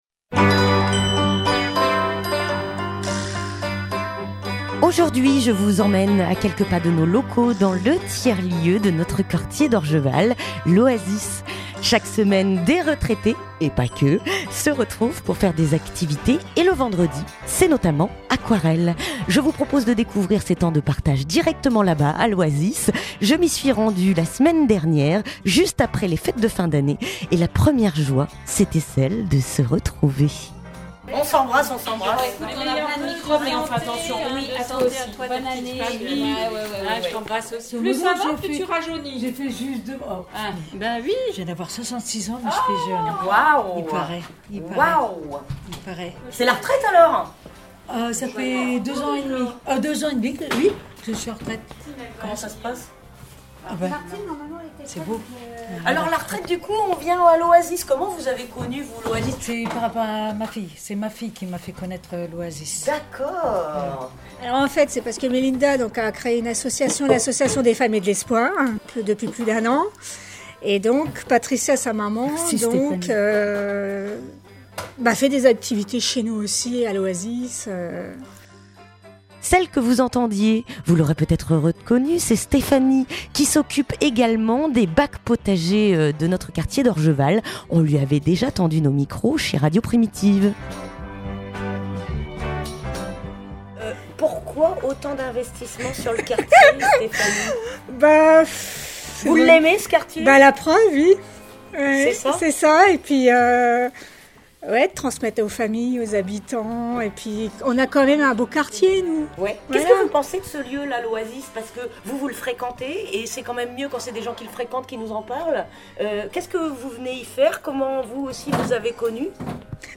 Reportage à l'Oasis (16:36)